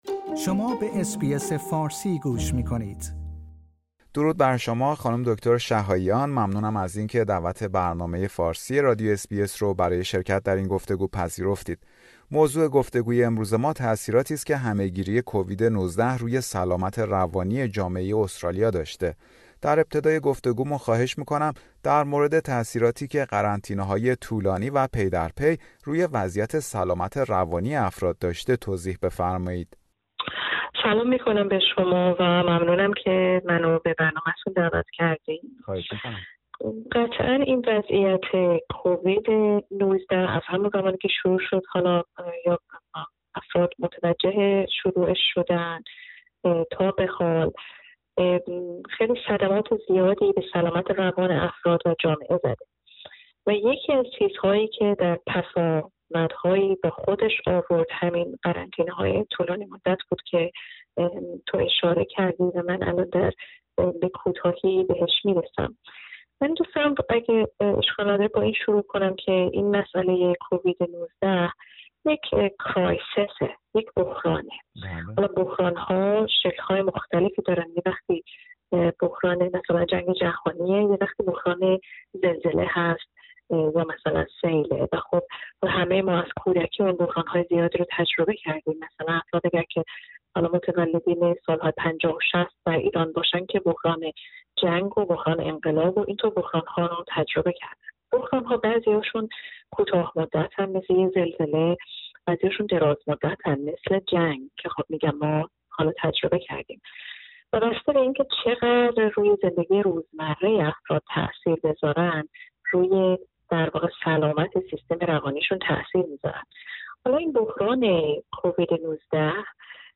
همه گیری کووید-۱۹ و شرایط ناشی از آن، از جمله قرنطینه های طولانی و پی در پی، روی سیستم سلامت روانی استرالیا تاثیر گذاشته است و تقاضا برای دسترسی به خدمات در این زمینه را افزایش داده است. برنامه فارسی رادیو اس بی اس در همین خصوص گفتگویی داشته